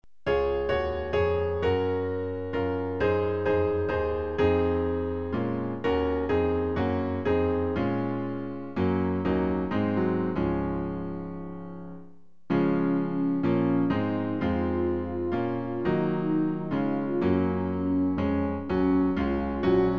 Klavier-Playback zur Begleitung der Gemeinde MP3 Download